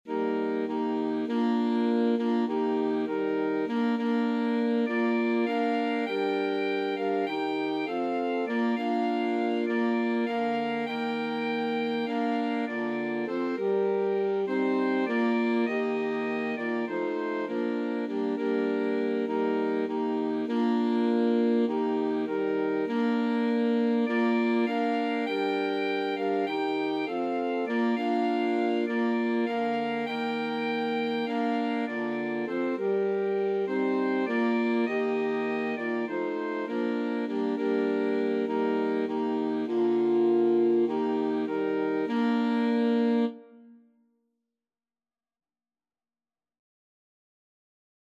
Alto Sax Quartet version
4/4 (View more 4/4 Music)
Scottish